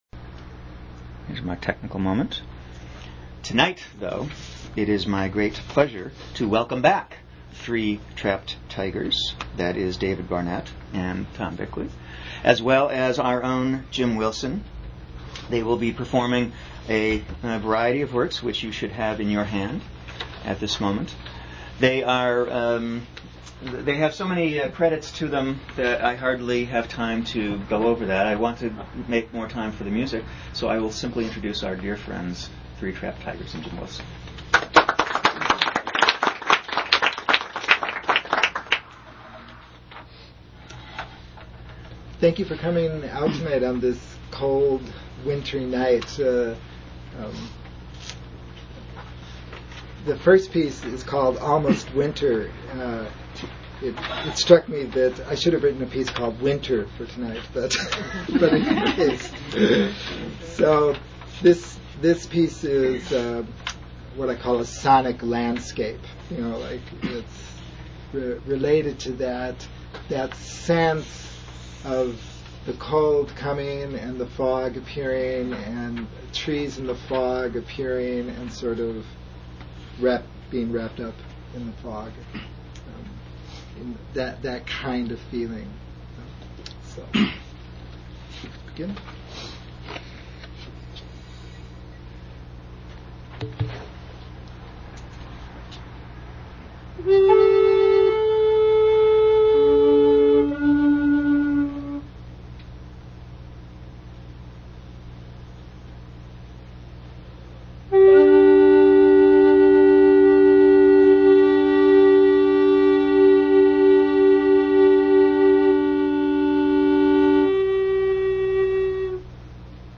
Archive of an event at Sonoma County's largest spiritual bookstore and premium loose leaf tea shop.
Recorder Concert: Almost Winter and Other Works
recorder ensemble
on three recorders
for solo alto recorder by Alun Huddinott.